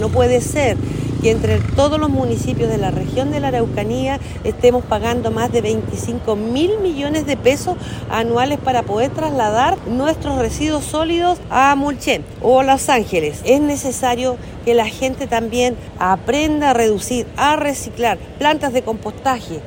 La también alcaldesa de Pitrufquén, sostuvo que es necesario avanzar en iniciativas de reciclaje reales y en el compostaje de los alimentos.